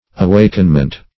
Awakenment \A*wak"en*ment\, n.